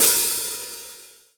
018_hho_Jazz_c.wav